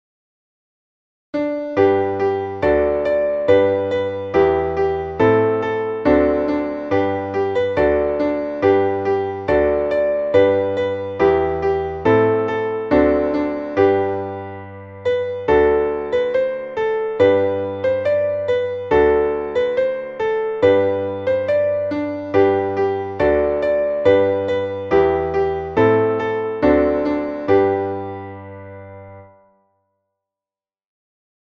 Traditionelles Kinderlied